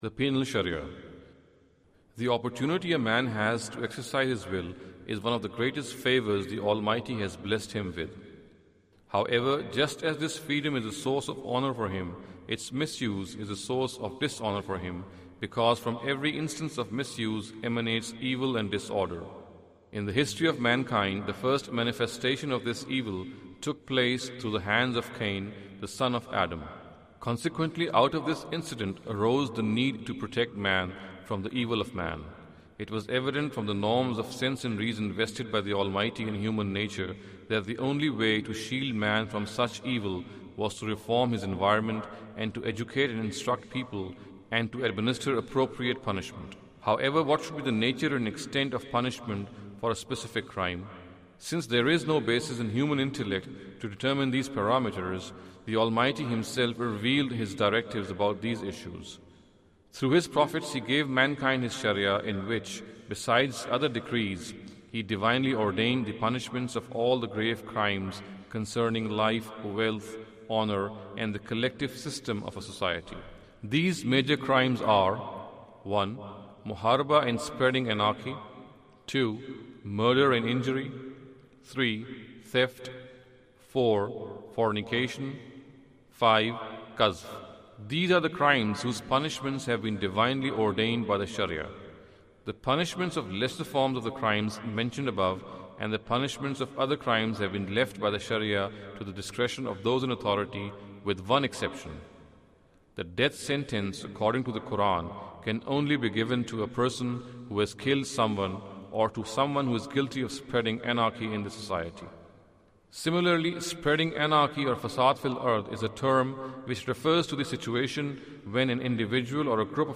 Category: Audio Books / Islam: A Concise Introduction /